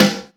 Boom-Bap Snare 89.wav